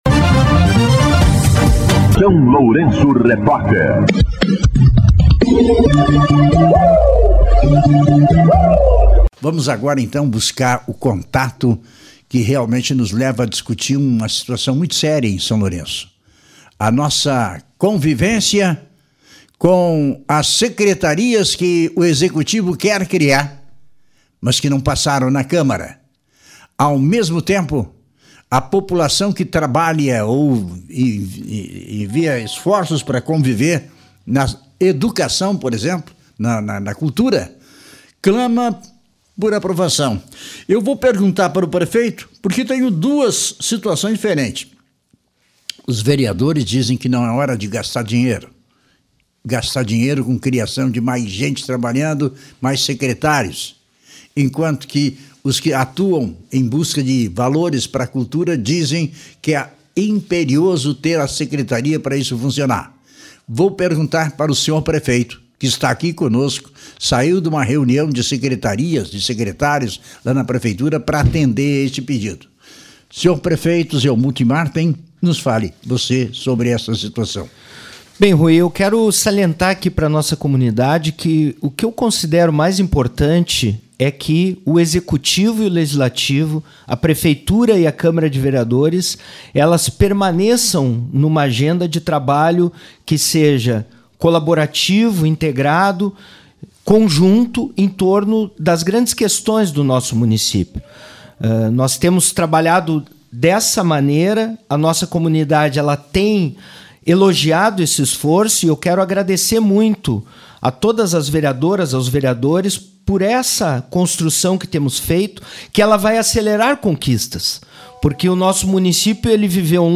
Entrevista com o Prefeito Municipal Zelmute Marten